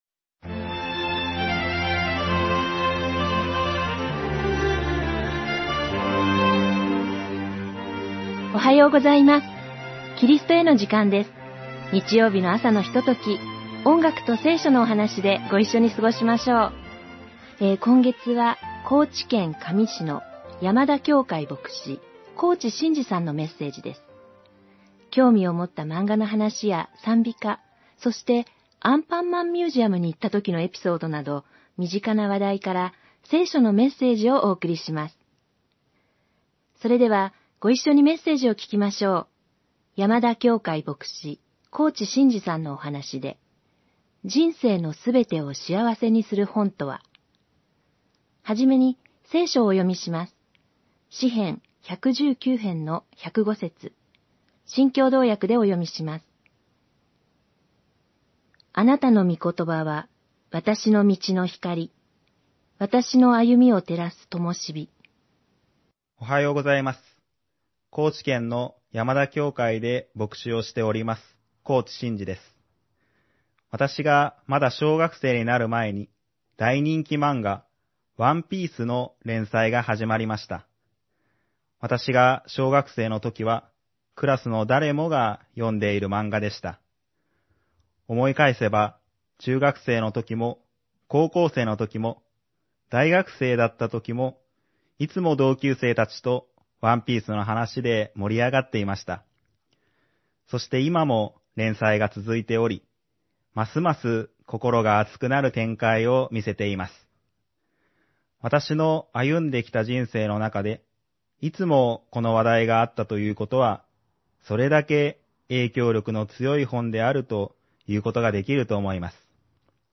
※ホームページでは音楽著作権の関係上、一部をカットして放送しています。